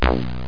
beep11.mp3